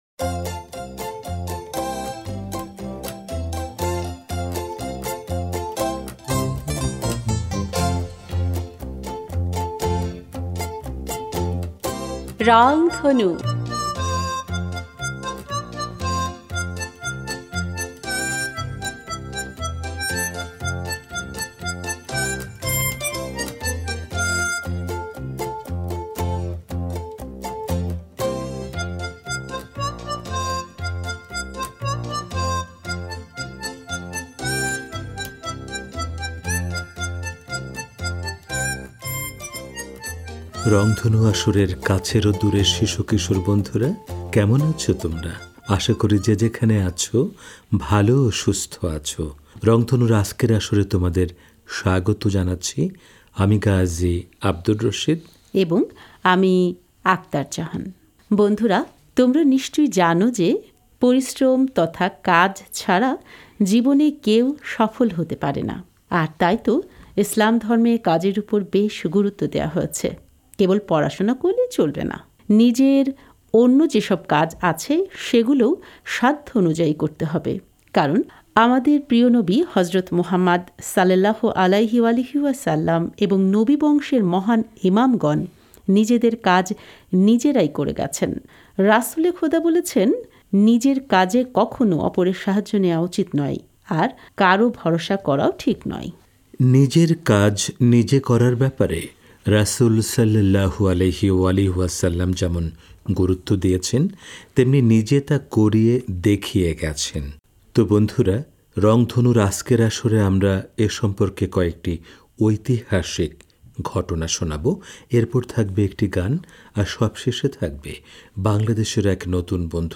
এরপর থাকবে একটি গান। আর সবশেষে থাকবে বাংলাদেশের এক নতুন বন্ধুর সাক্ষাৎকার।